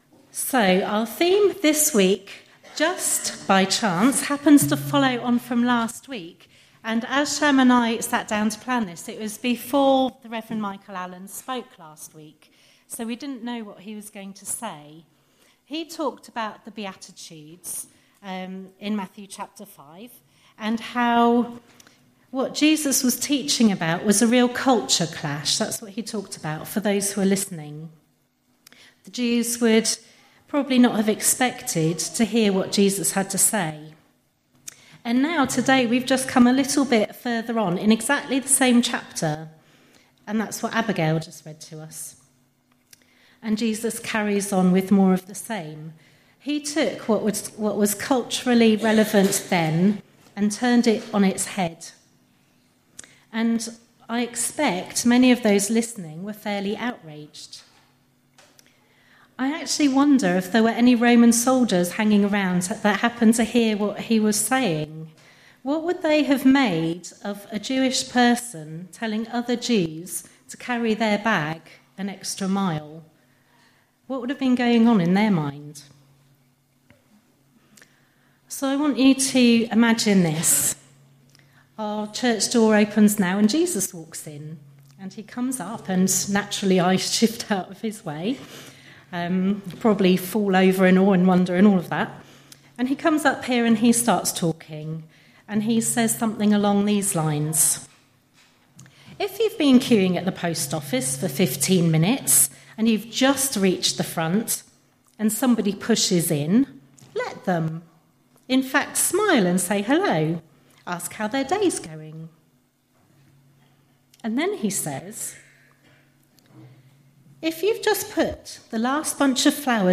A service accessible to all ages with a focus on including the younger elements of the congregation.